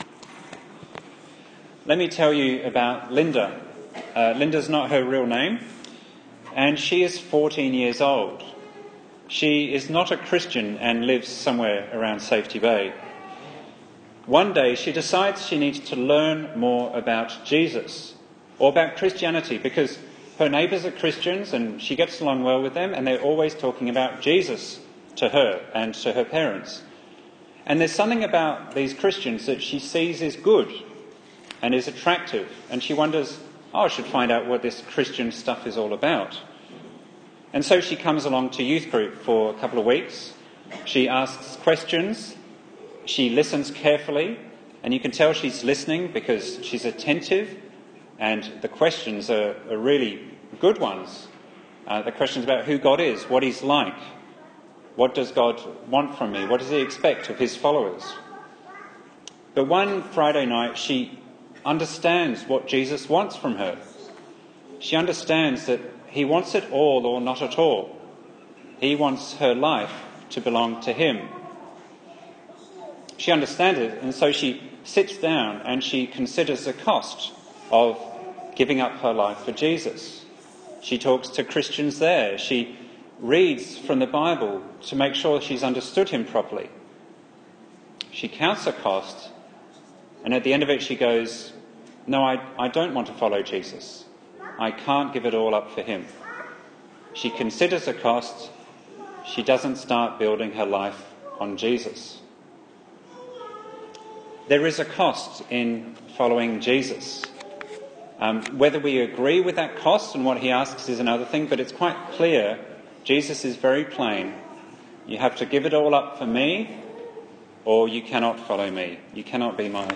Bible Text: Luke 14:25–35 | Preacher